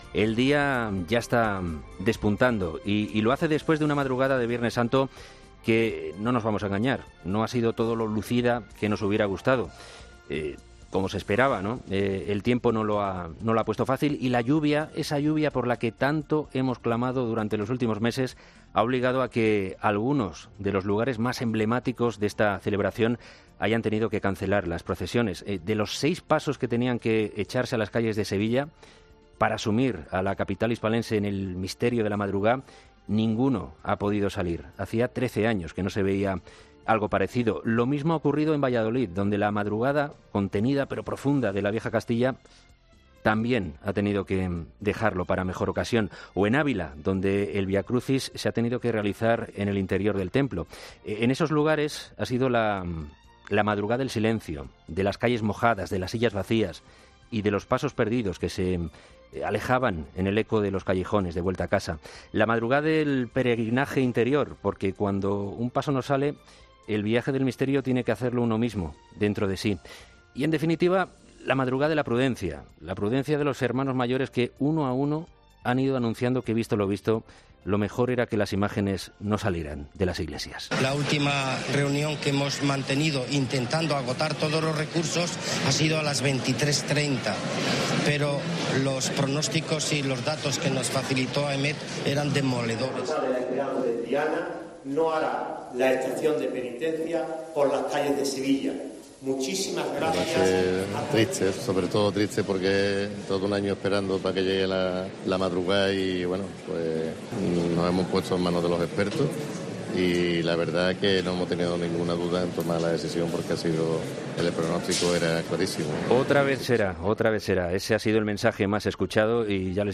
Momento en el que se le comunica a los hermanos que por las inclemencias del tiempo no se puede realizar la estación de penitencia